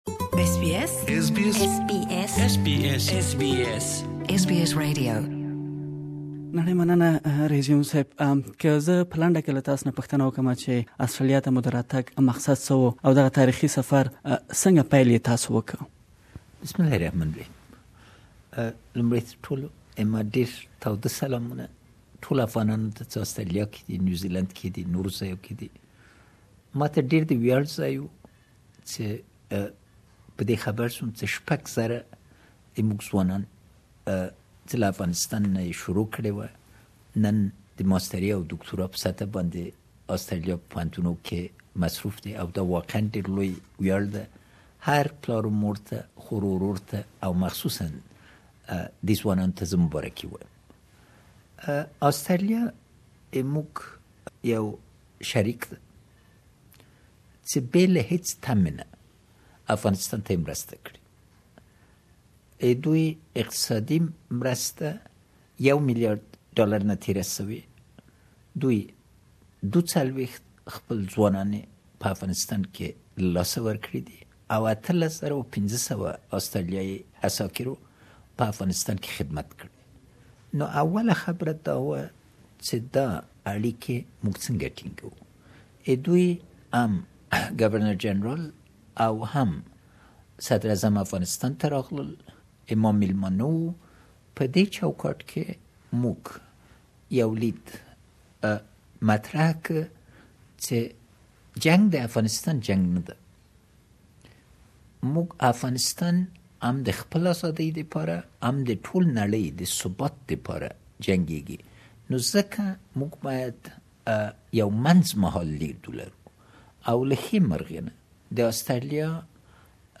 Afghanistan's President Mohammad Ashraf Ghani tells SBS Radio Pashto that his priority is ensuring that Afghan migrants returning home will be granted opportunities and jobs. His landmark four day tour of Australia is the first of its kind ever undertaken by an Afghan President.